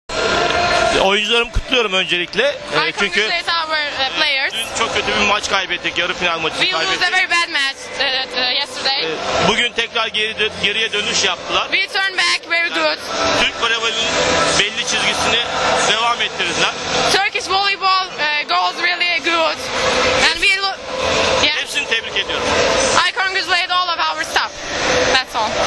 IZJAVA
SA PREVODOM